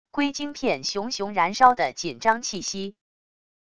硅晶片熊熊燃烧的紧张气息wav音频